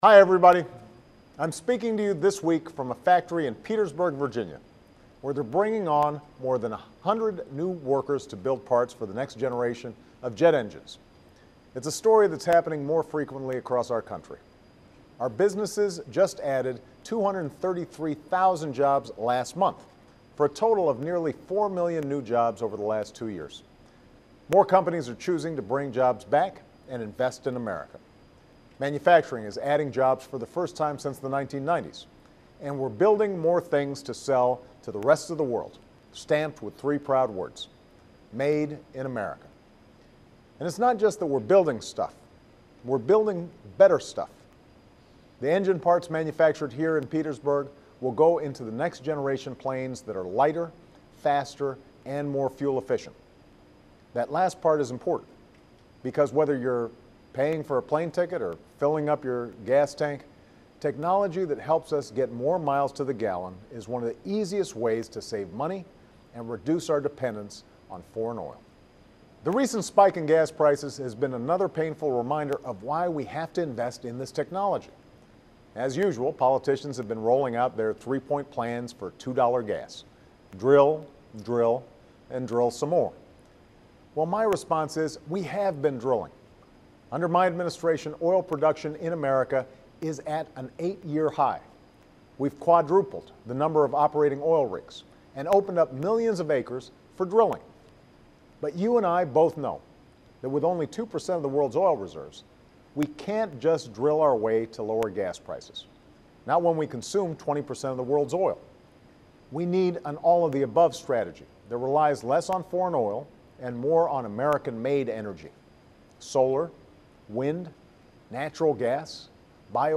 演讲听力材料03.11
Remarks of President Barack Obama
Petersburg, Virginia